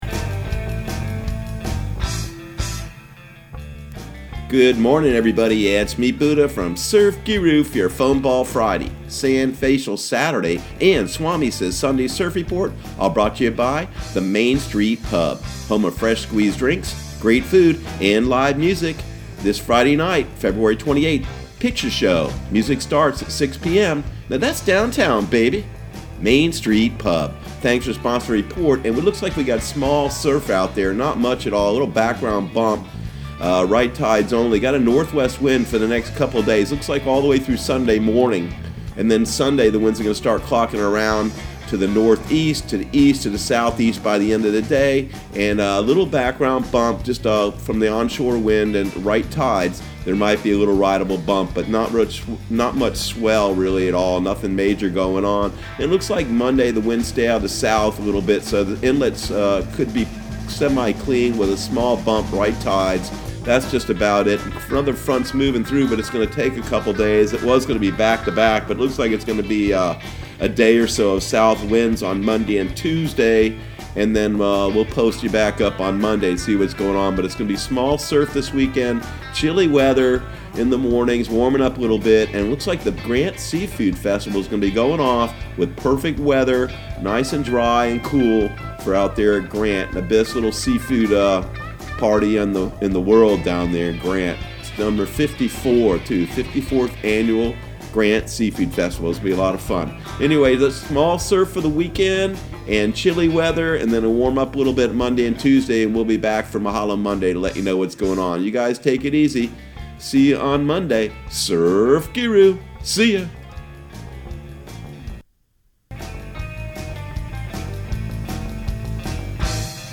Surf Guru Surf Report and Forecast 02/28/2020 Audio surf report and surf forecast on February 28 for Central Florida and the Southeast.